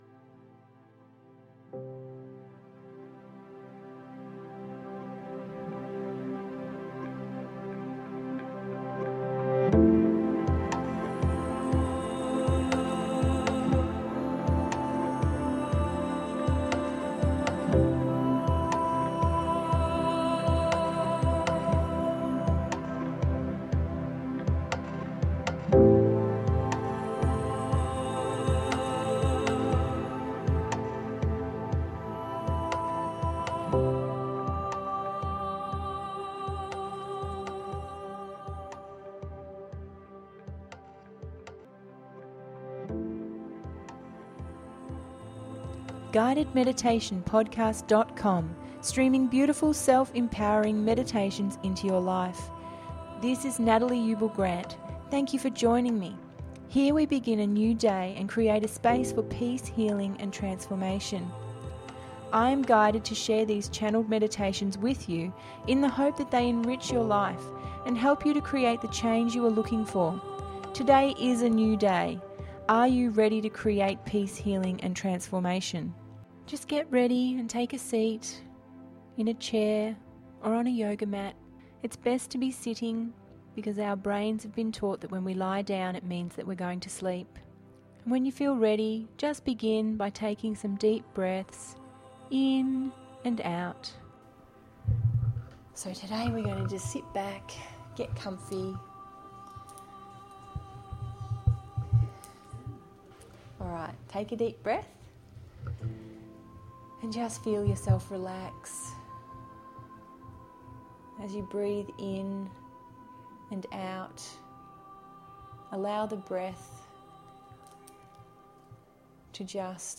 Release And Reset…058 – GUIDED MEDITATION PODCAST